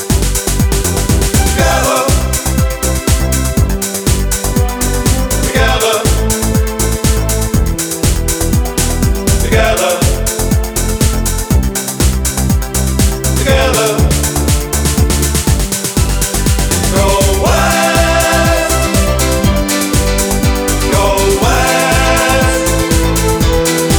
No Female Solo Vocal Pop (1980s) 4:30 Buy £1.50